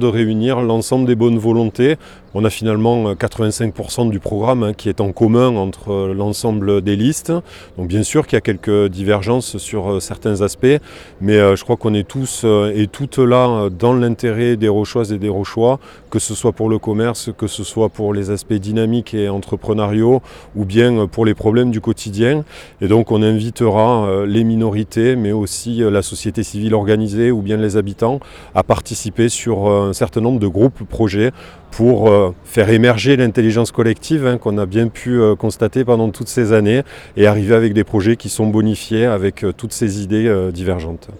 Le futur nouveau maire évoque la méthode de travail de son équipe.